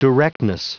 Prononciation audio / Fichier audio de DIRECTNESS en anglais
Prononciation du mot directness en anglais (fichier audio)